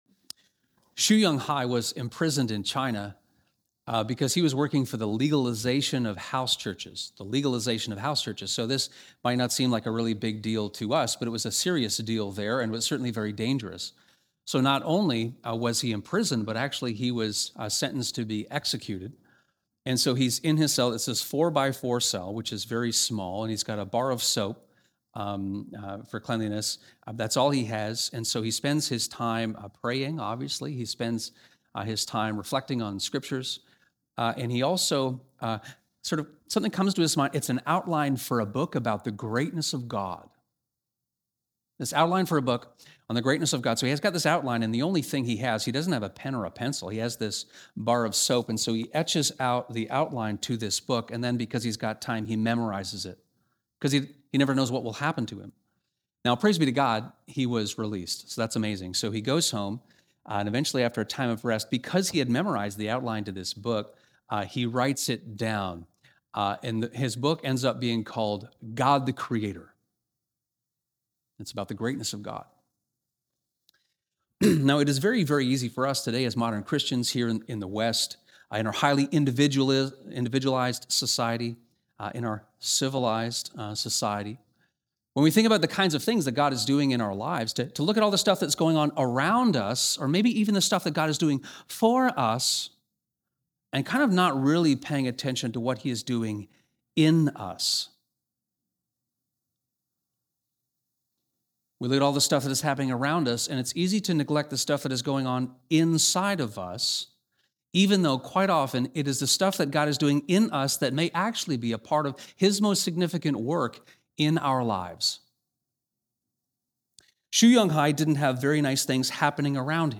This sermon explores Daniel 8, his second vision.